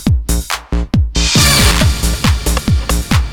стаккато и кусок стаба